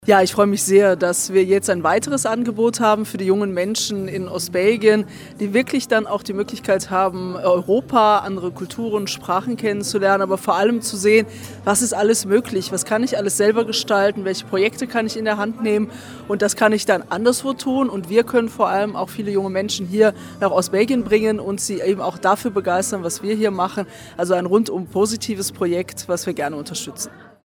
Die zuständige Ministerin, Isabelle Weykmans zeigte sich erfreut über die EU-Initiative: